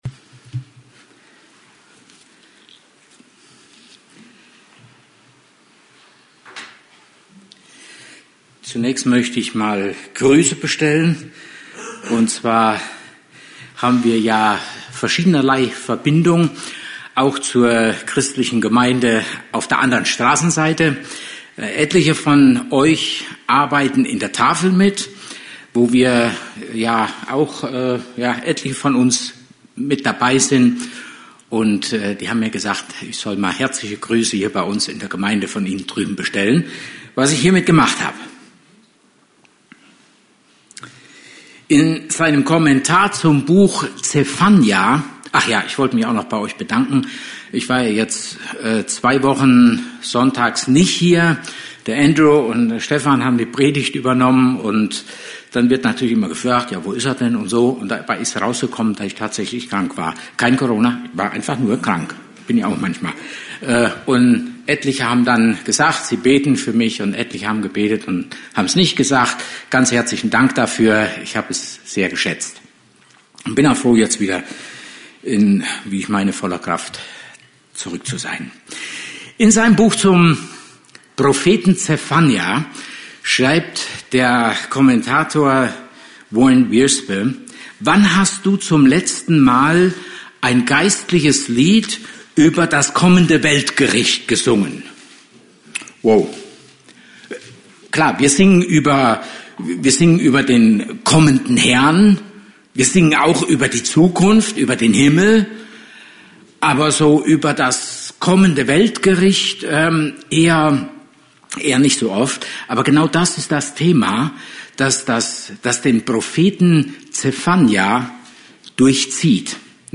Service Type: Gottesdienst